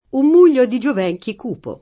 mugghio [m2ggLo] s. m.; pl. -ghi — pop. o poet. muglio [m2l’l’o]; pl. mugli — es.: Il mugghio nel sereno aer si perde [il m2ggLo nel Ser%no #-er Si p$rde] (Carducci); un muglio di giovenchi Cupo [